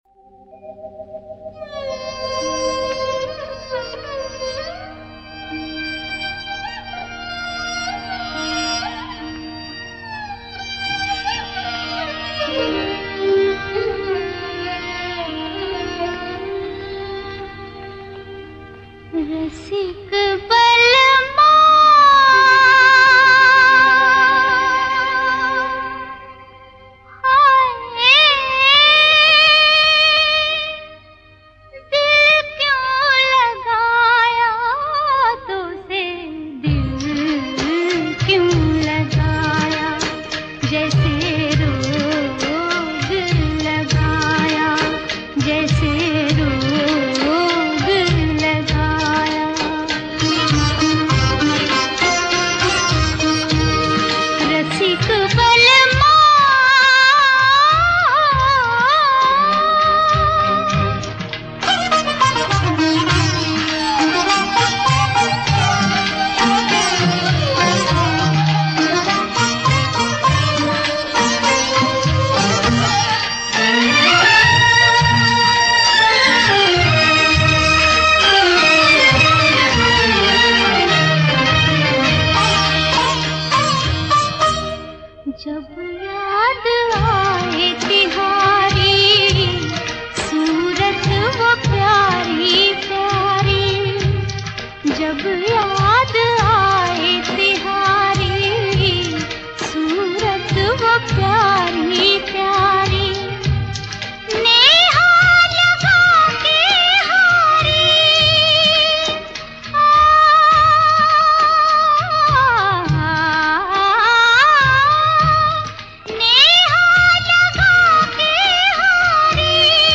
旋律优雅 完美